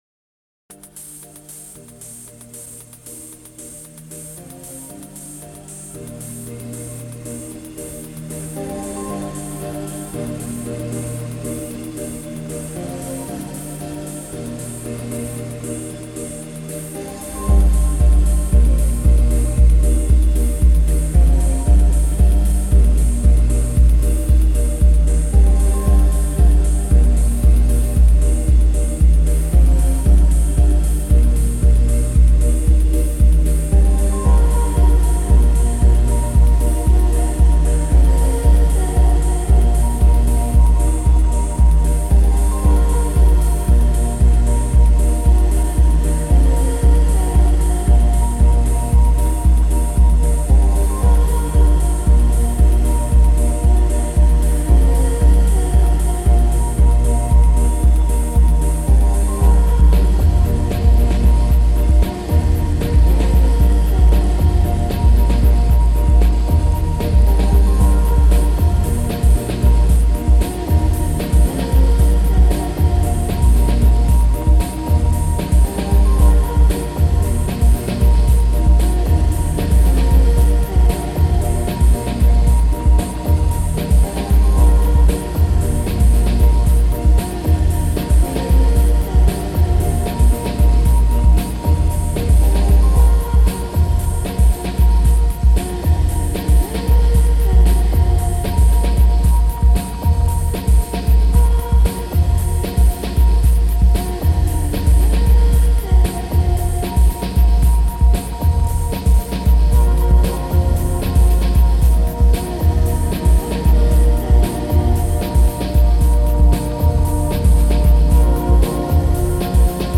DIY ambience and womblike pulses